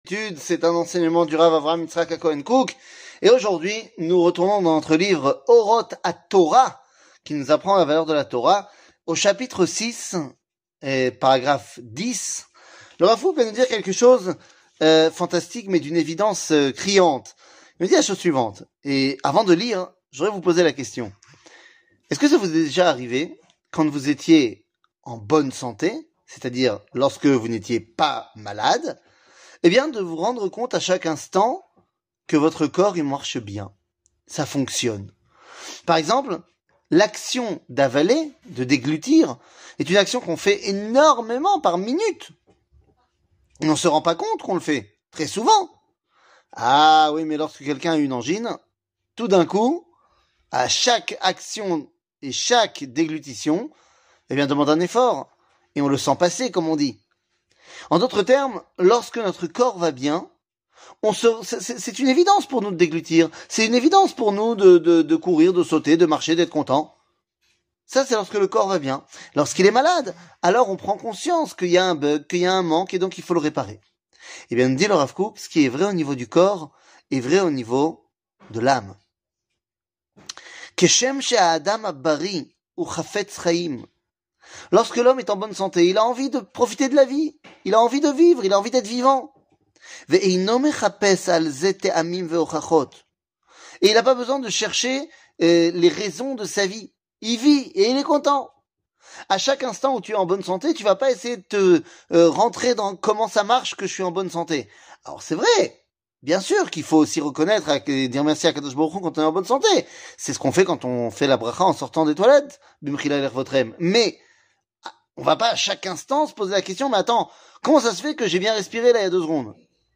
שיעור מ 17 ינואר 2023
שיעורים קצרים